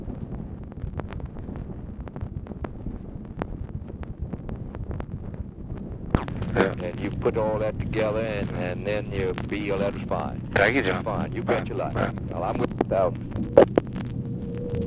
Conversation with JOHN STENNIS, May 4, 1965
Secret White House Tapes